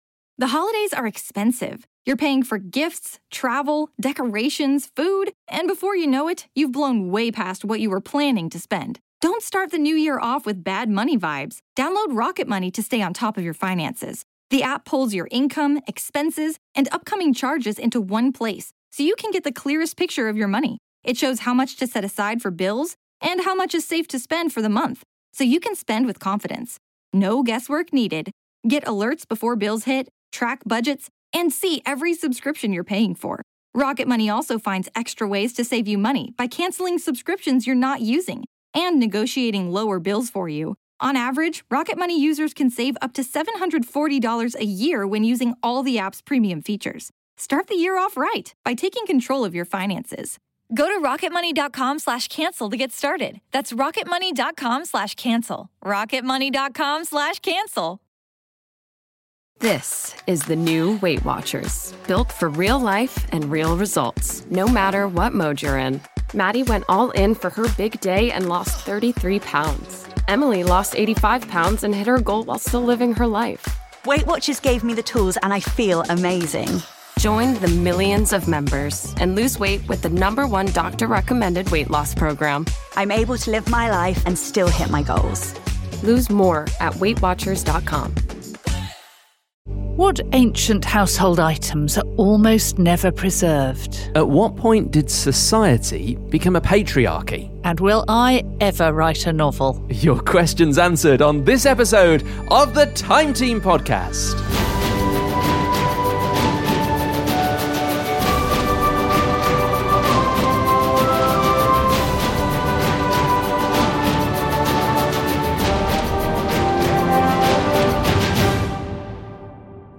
answers questions from Time Team members in this episode of the podcast.